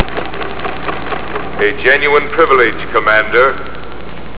Here are some sounds from the movie: